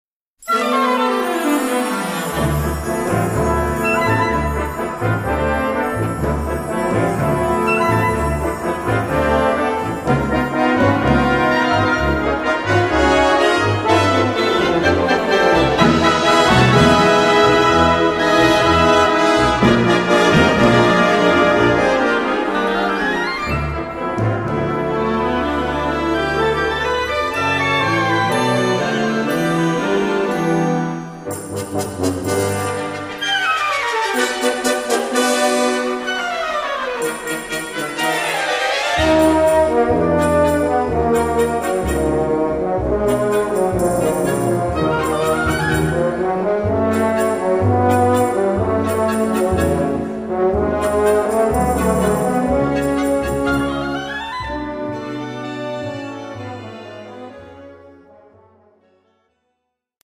Gattung: Tondichtung
A4 Besetzung: Blasorchester Zu hören auf